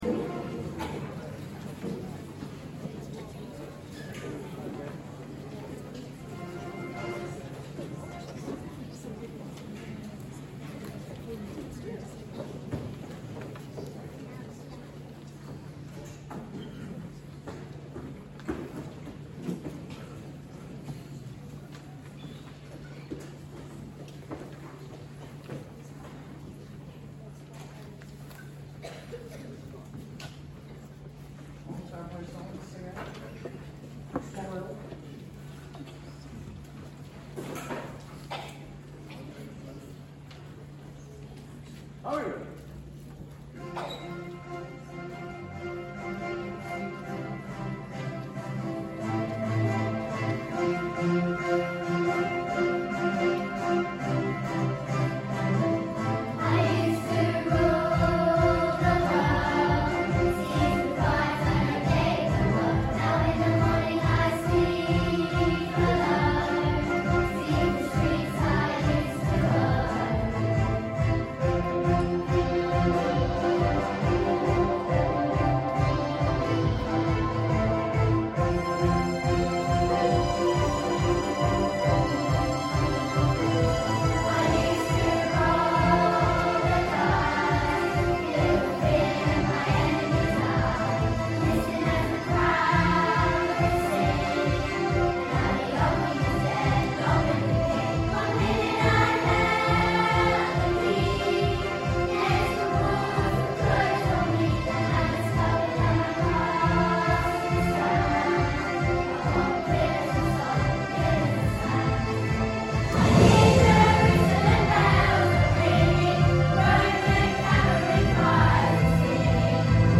Viva La Vida | Year 4/5/6 Choir